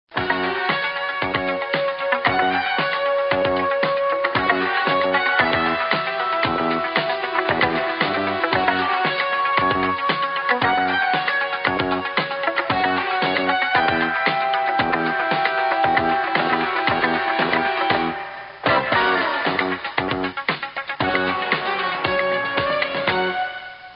end credit music